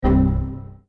На этой странице собраны классические звуки Windows XP, которые стали символом эпохи.
Звук критической ошибки Windows XP